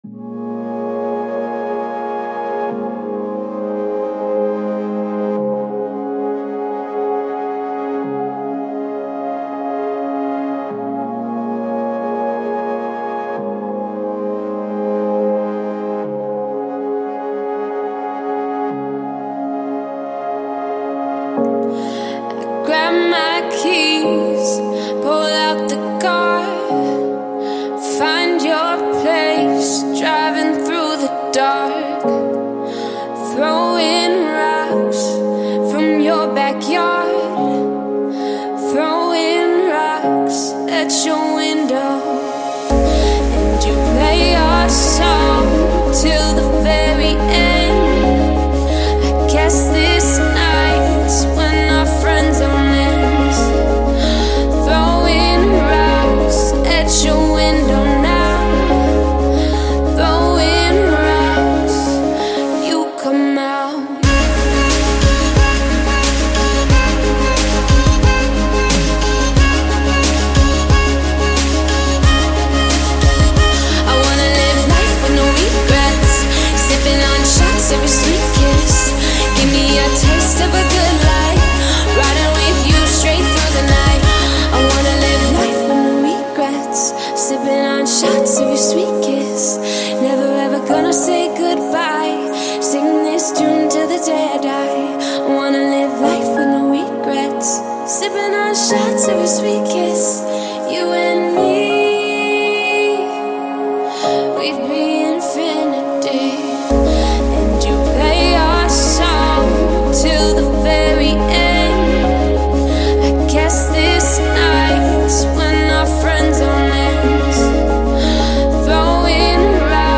Стиль: DubStep / Chillout/Lounge